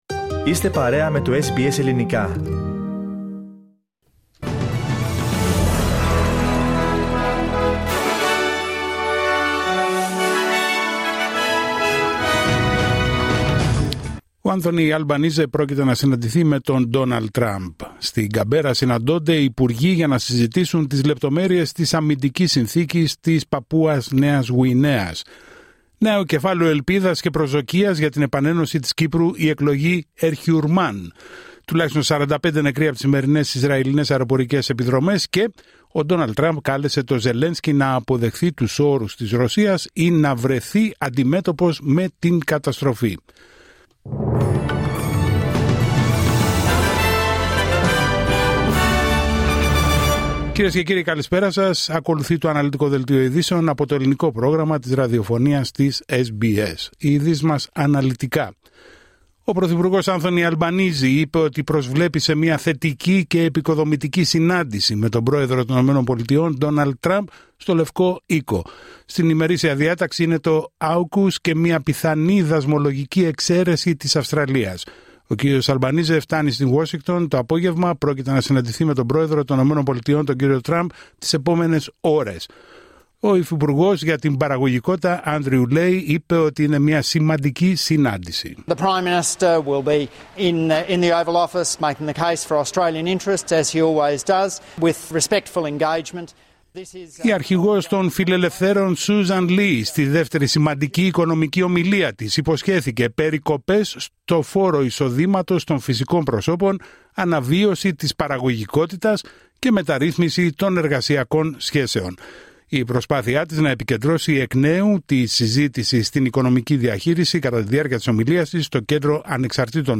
Δελτίο ειδήσεων Δευτέρα 20 Οκτωβρίου 2025